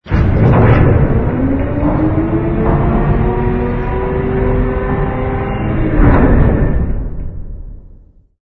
jumpgate_open.wav